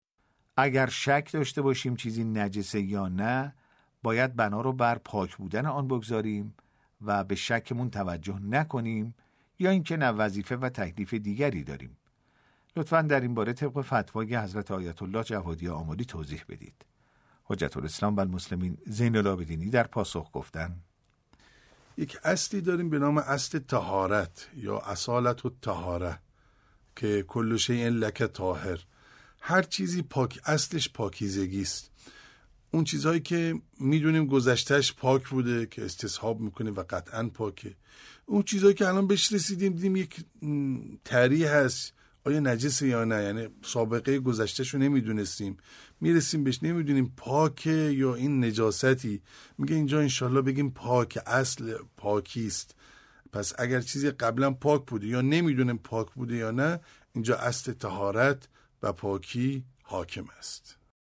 پاسخ نماینده دفتر حضرت آیت الله العظمی جوادی آملی